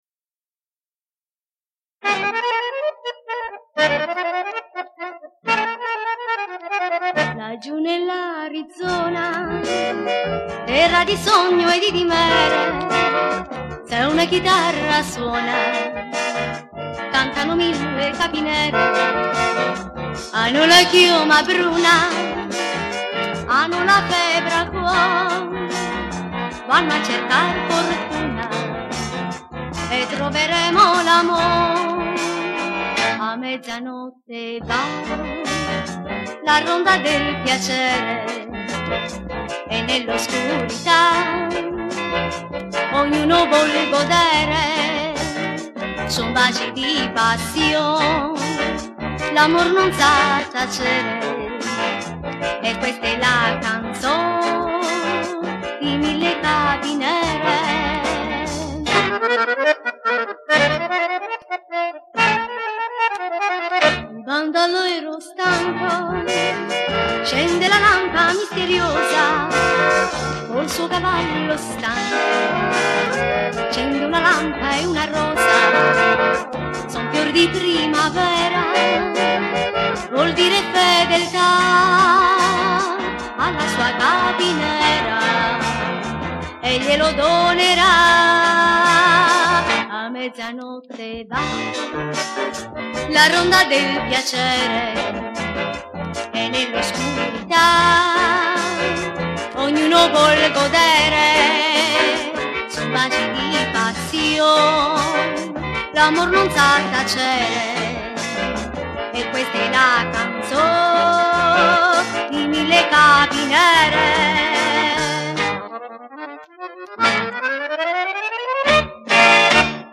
Piano Accordion, Organ, Strings & Electric Piano
Electric & Acoustic Guitars
Electric Bass
Drums
Brass Section
BACKGROUND VOCALS
Recorded at Riversound Recordings, Sydney